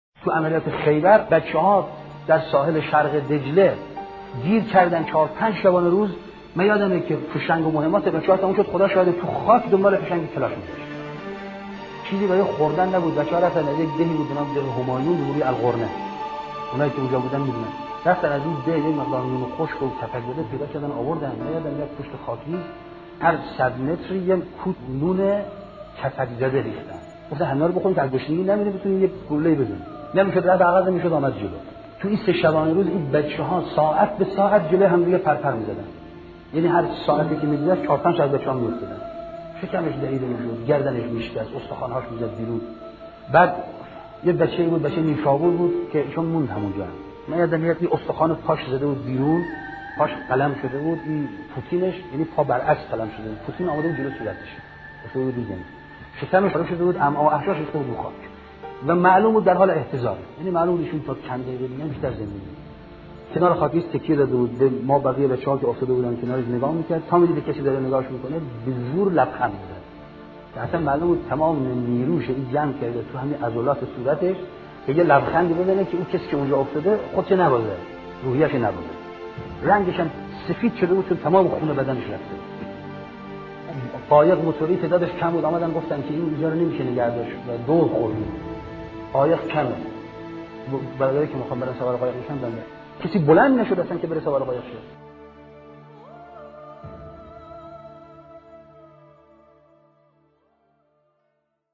ravayatgari122.mp3